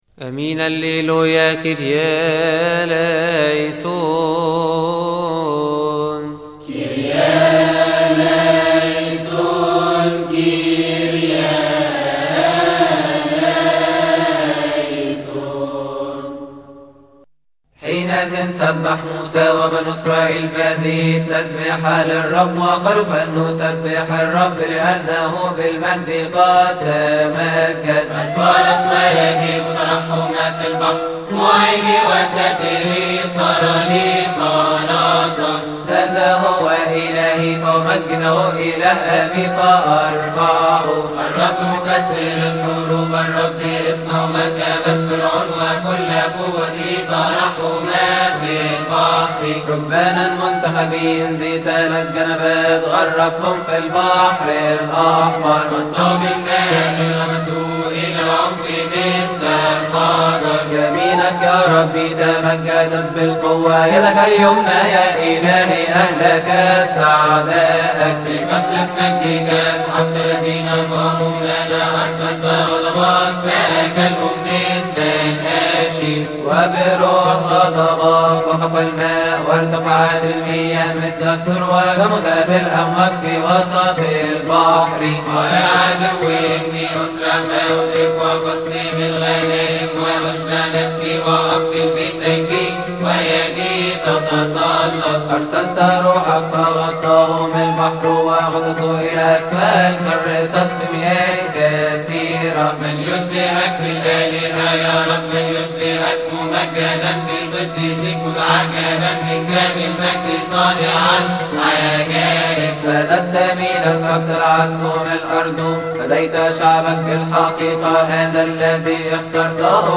استماع وتحميل لحن الهوس الاول عربى من مناسبة keahk